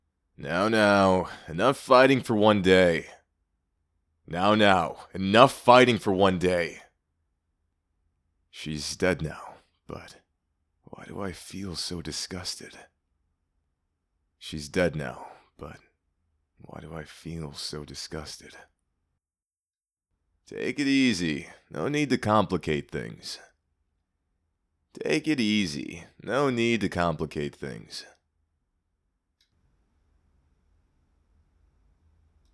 Voice: Rindoh's voice is pretty much up for interpretation, however, I would like for it to be in the medium to deep range and possibly outgoing.